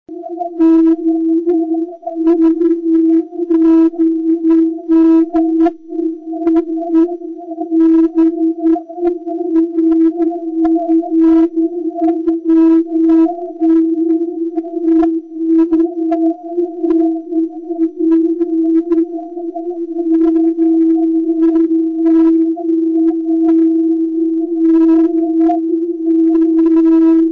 Overview of the Ham Radio Beacons in OK & OM